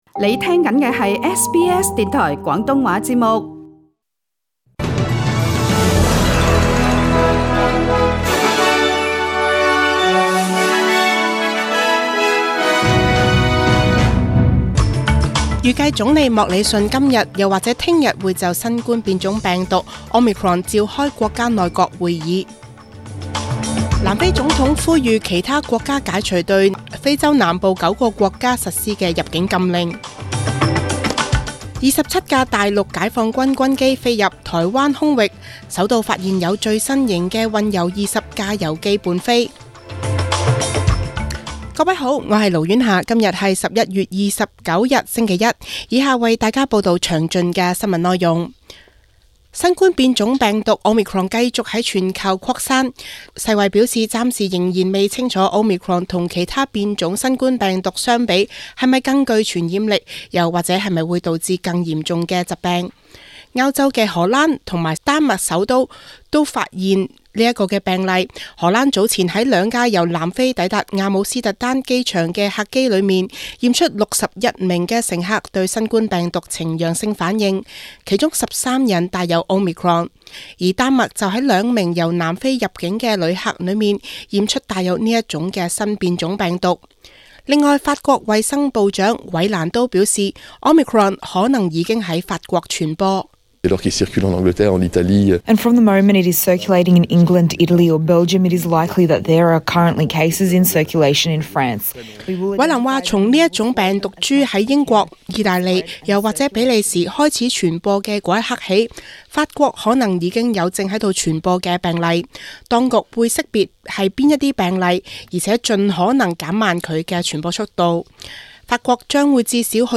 SBS中文新聞(11月29日)